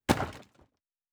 Fantasy Interface Sounds
Wood 12.wav